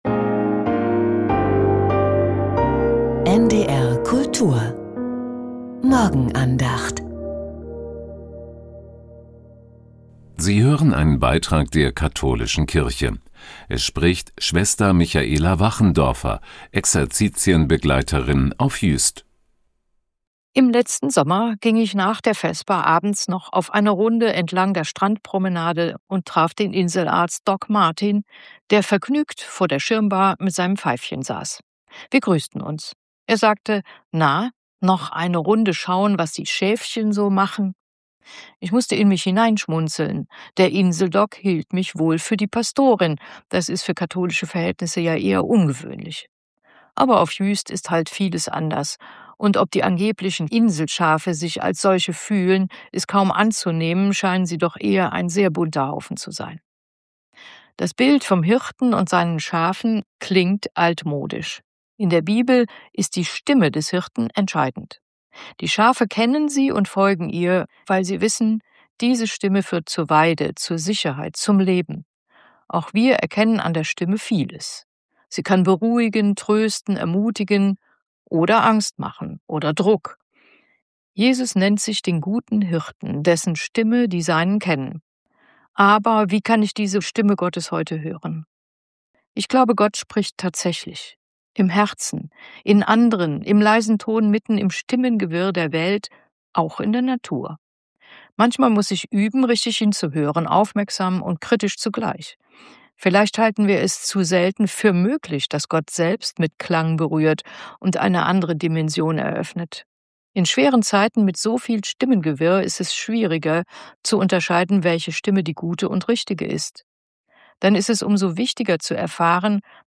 Die Morgenandacht